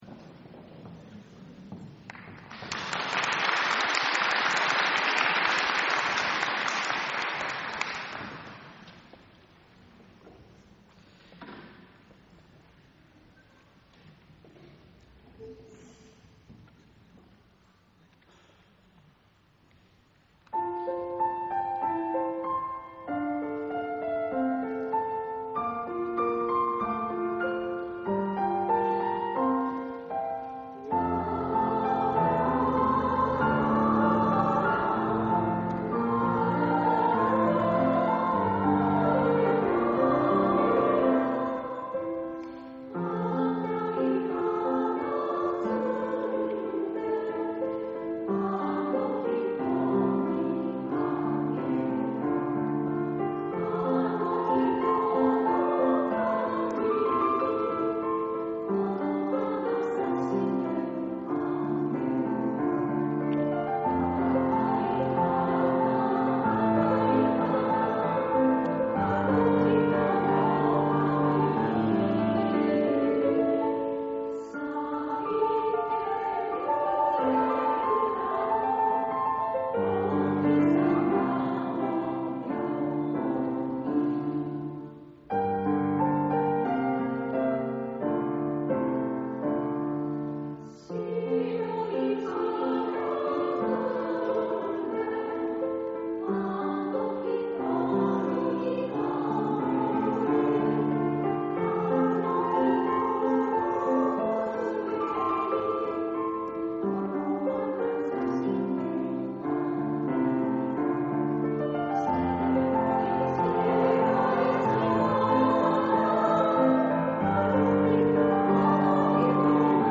第６０回台東区合唱祭が、平成２７年１１月１５日（日）に東京藝術大学奏楽堂で開催されました。
その時の各団体の演奏です。